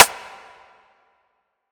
Rim [ verb ].wav